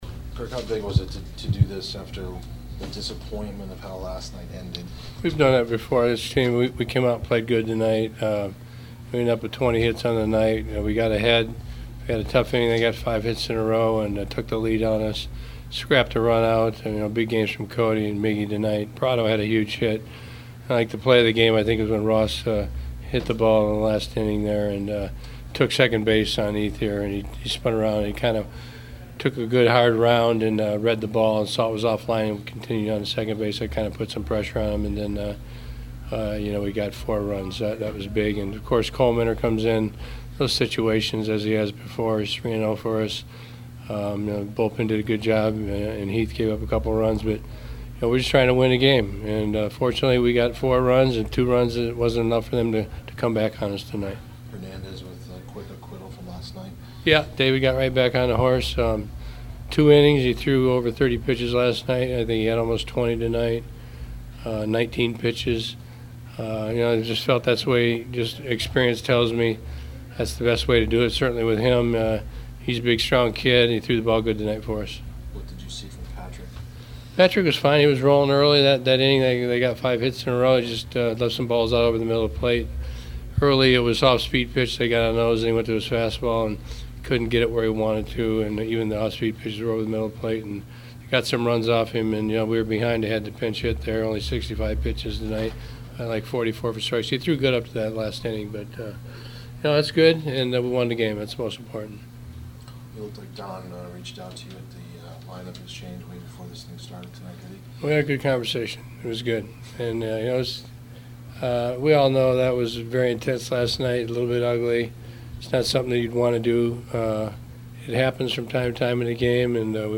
Diamondbacks manager Kirk Gibson postgame thoughts:
kirk-gibson-postgame-after-series-win-over-dodgers.mp3